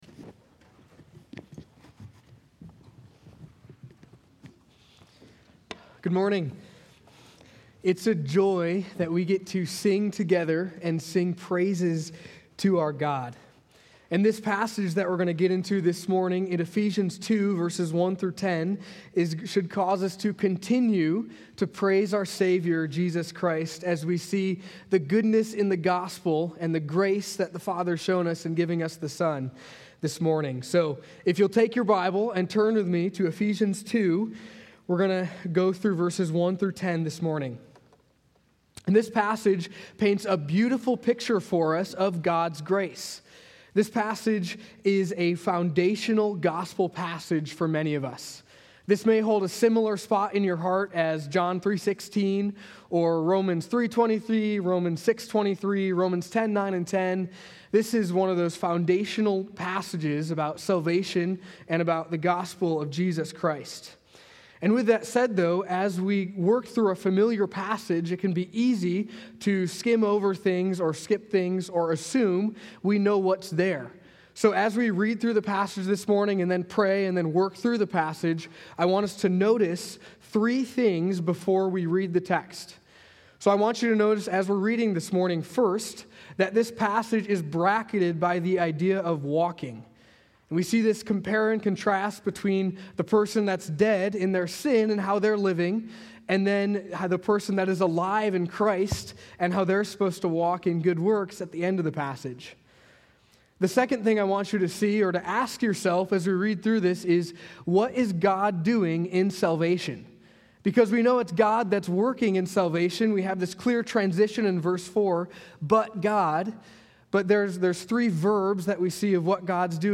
A Prayer For The Church | Baptist Church in Jamestown, Ohio, dedicated to a spirit of unity, prayer, and spiritual growth